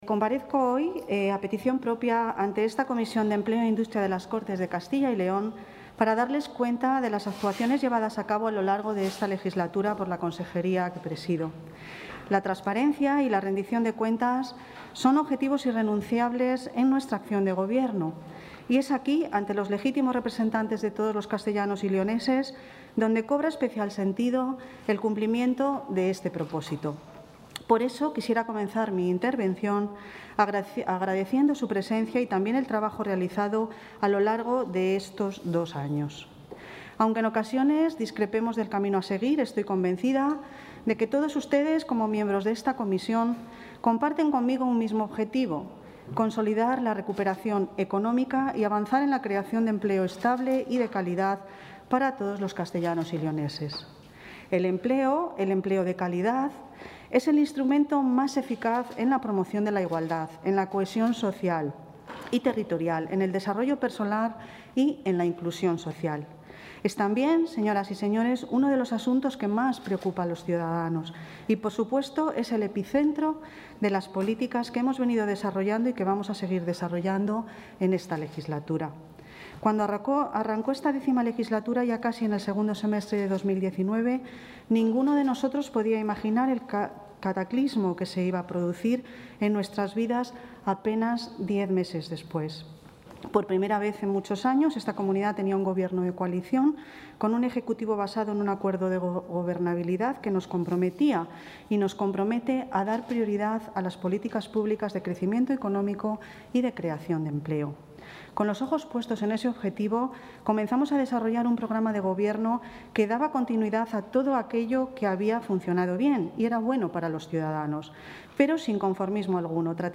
La consejera de Empleo e Industria, Ana Carlota Amigo, ha comparecido hoy, a petición propia, en la Comisión de Empleo e Industria de...
Comparecencia de la consejera de Empleo e Industria.